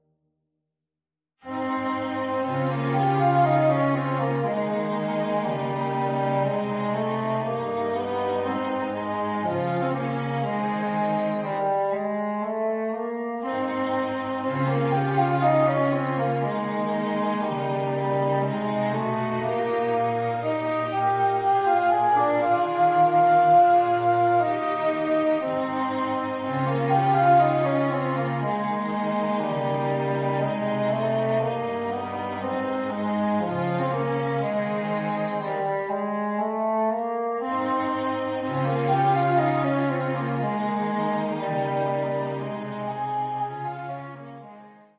bassoon, violin, viola, violoncello
2. Adagio
(Audio generated by Sibelius)